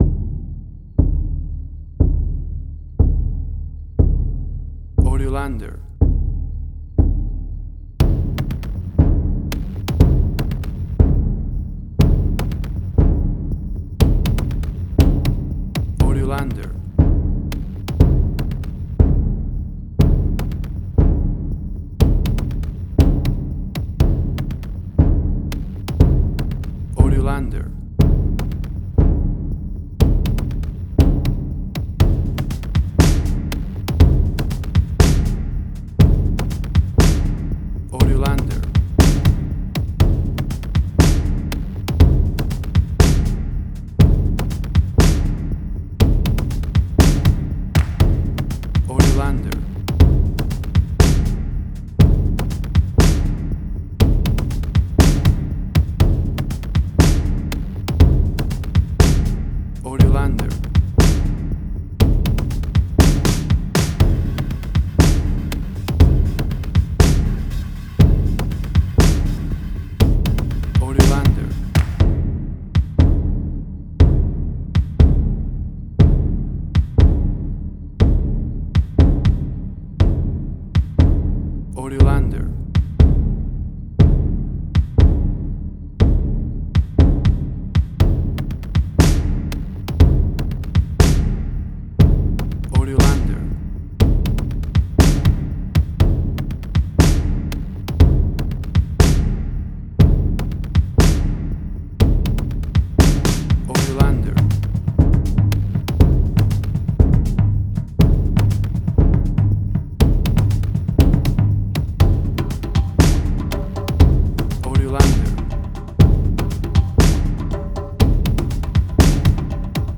Suspense, Drama, Quirky, Emotional.
Tempo (BPM): 120